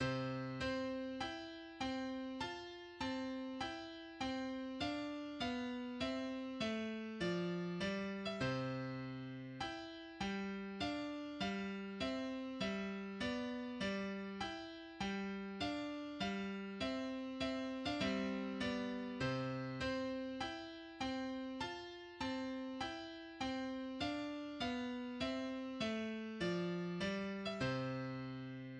This work was composed for solo piano and consists of the theme (transcribed below) and 12 variations. Only the final two variations have tempo indications, Adagio and Allegro respectively.[2]